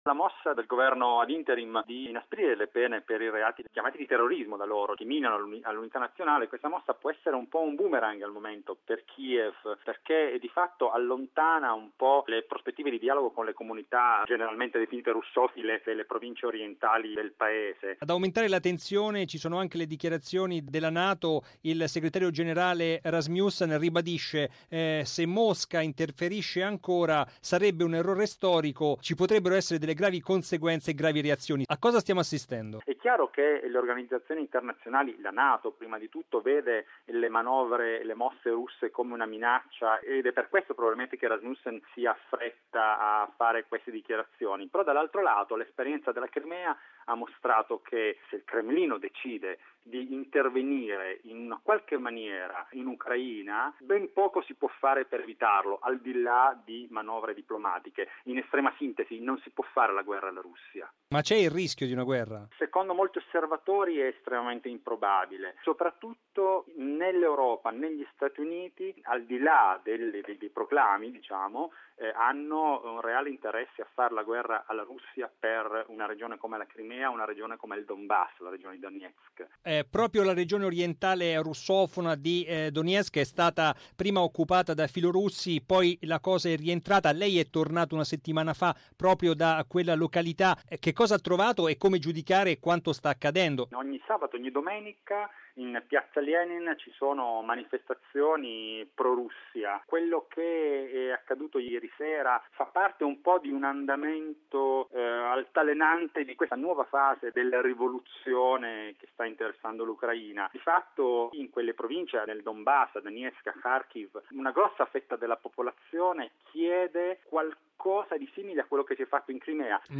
ha intervistato